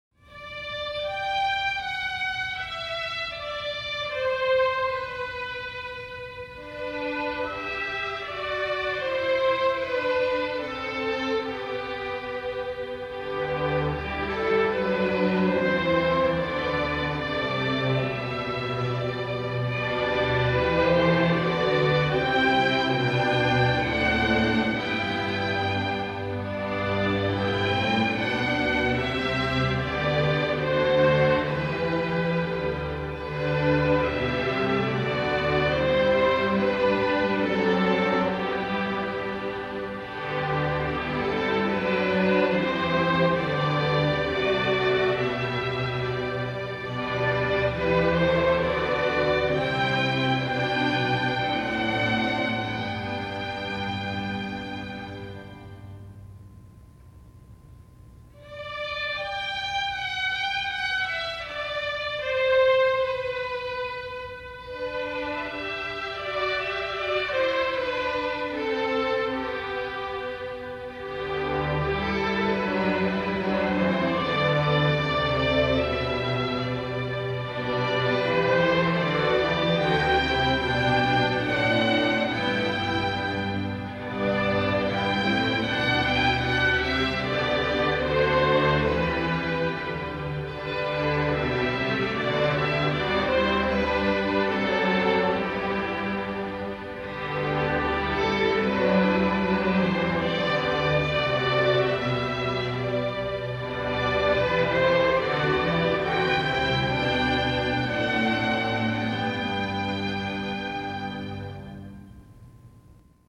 deux canons très faciles pour débutants
qui peuvent aussi être exécutés par violons seuls